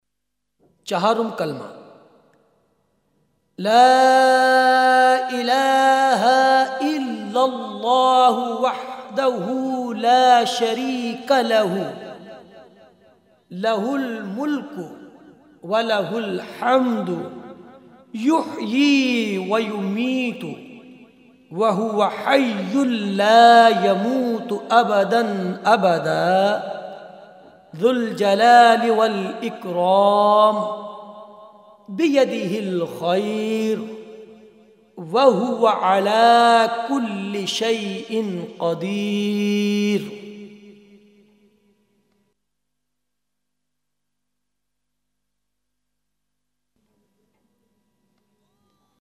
6 Kalima in Islam – Arabic Recitation
4th-Qalma-Tawheed.MP3